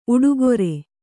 ♪ uḍugore